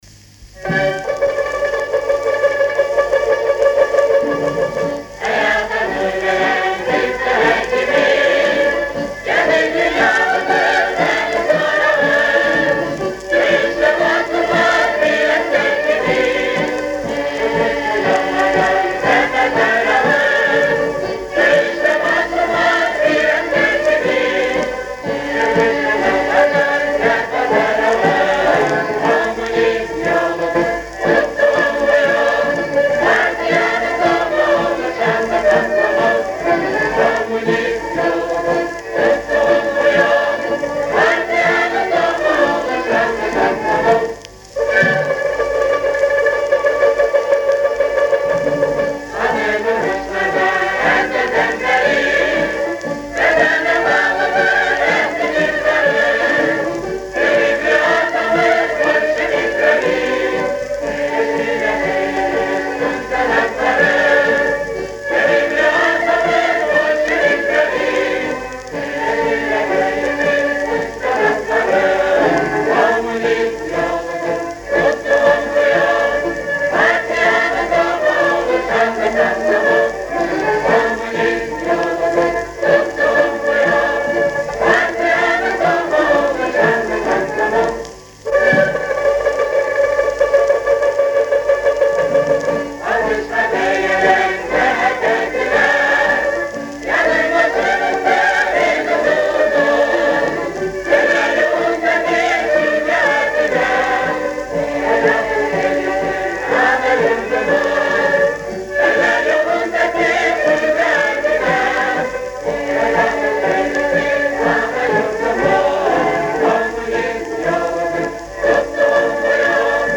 орк. нар. инстр.